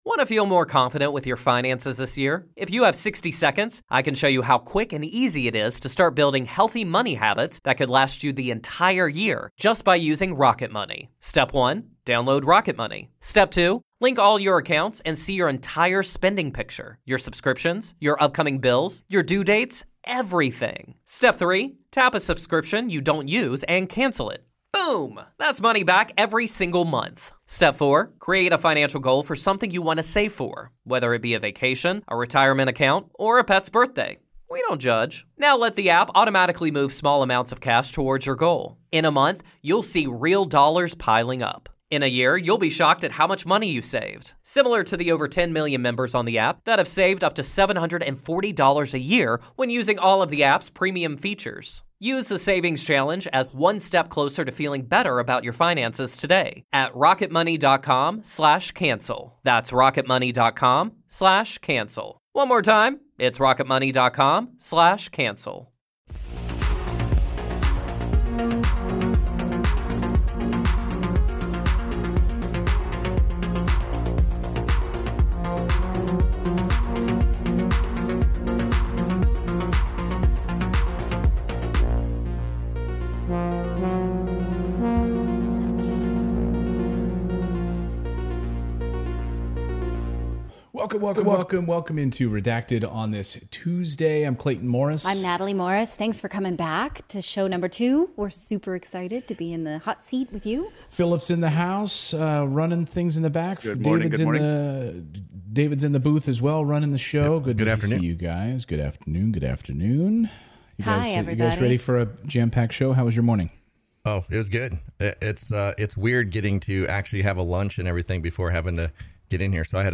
We're diving deeper into the new Pfizer vaccine documents. The U.S. government admits human interactions with UFO's in new documents. And President Biden calls for war crimes trial for Putin. Note: YouTube pulled us from the LIVE during the show, so this ends abruptly.